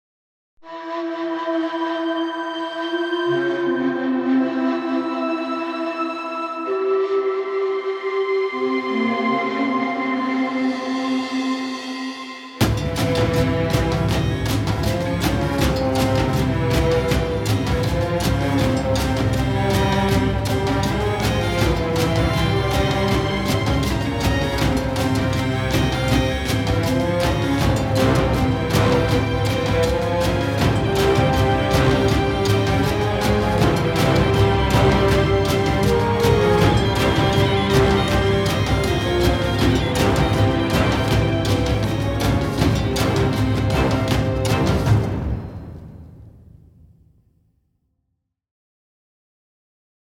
Cinematic action-adventure track for trailers.